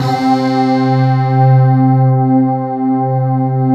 PAD K-20008L.wav